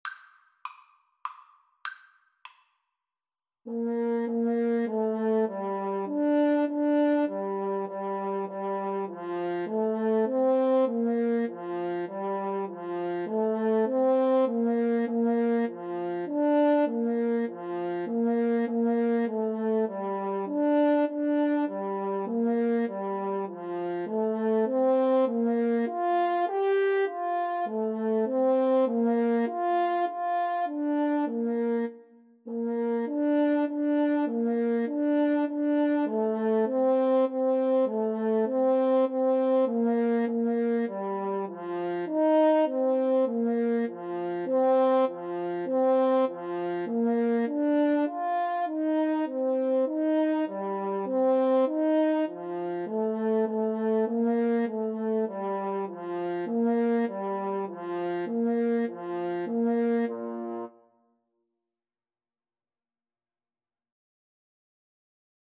3/4 (View more 3/4 Music)
Bb major (Sounding Pitch) (View more Bb major Music for Clarinet-French Horn Duet )
Traditional (View more Traditional Clarinet-French Horn Duet Music)